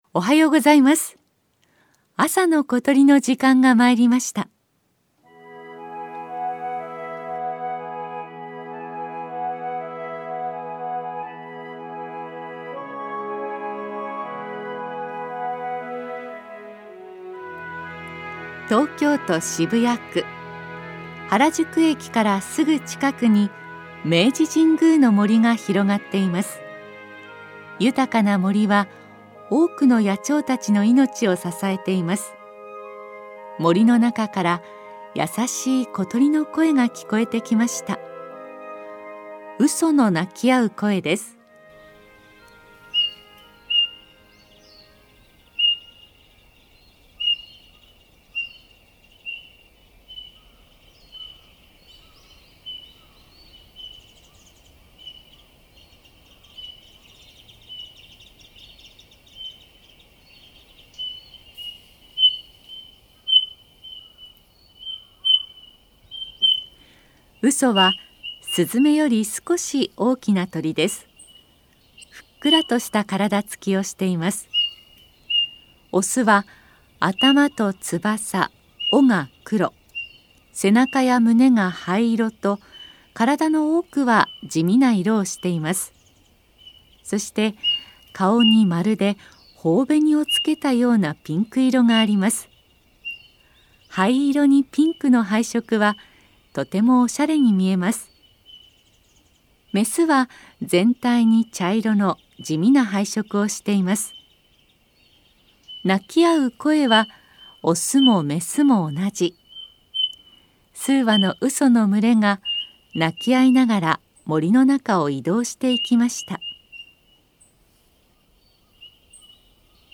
日本国中にとどまらず、広く海外に迄音源を求めて収録した鳥の声をご紹介しています。
明治神宮のウソをお届けします。